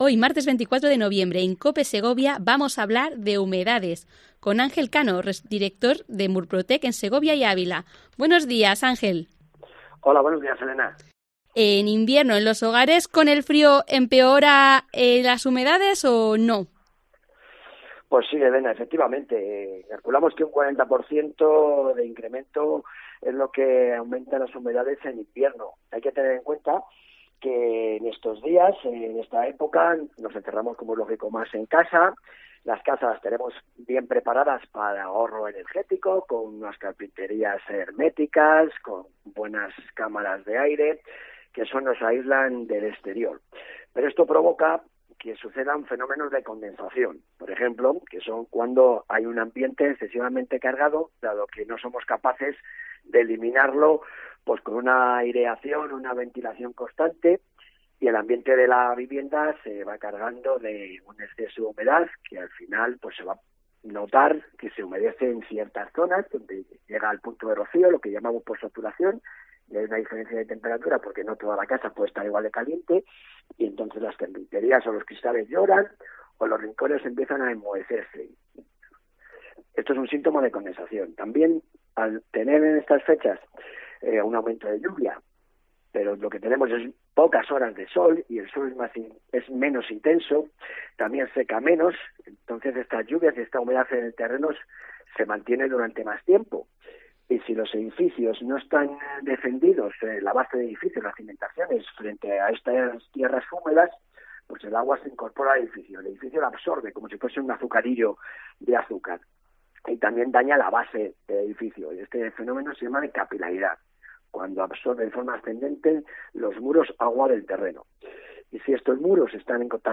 Entrevista a Murprotec